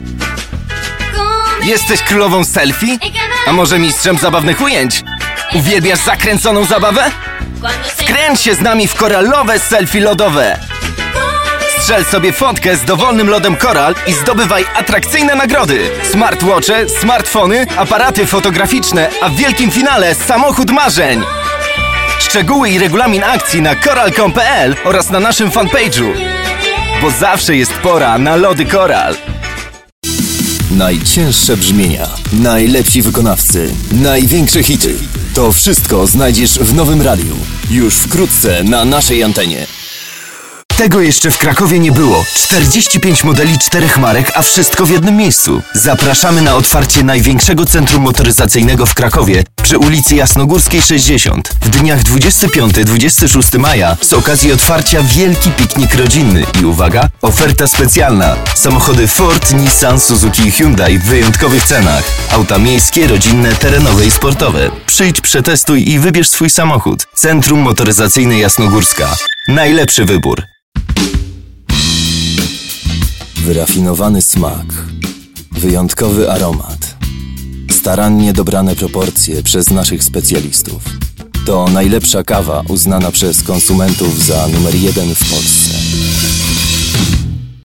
Male 30-50 lat
Demo lektorskie
Spot reklamowy